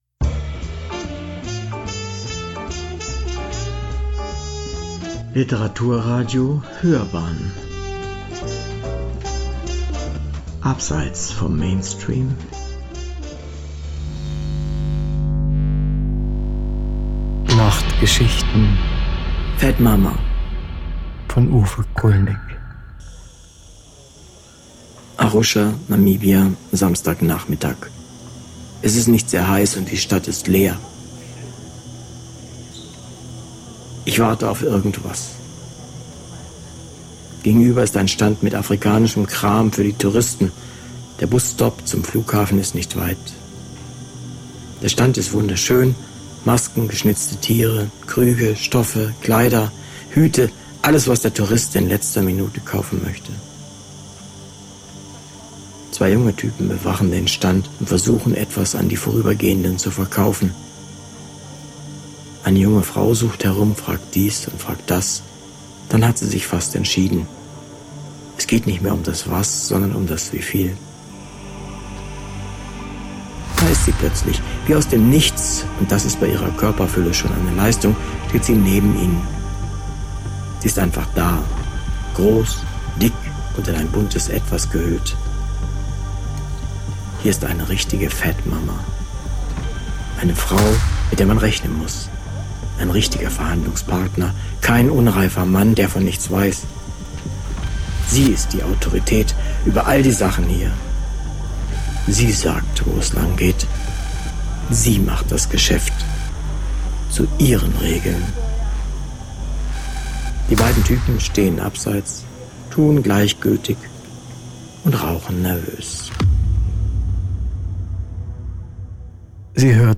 Sie werden nicht nur gehört – sie werden erlebt.
Autor, Sprecher, Realisation: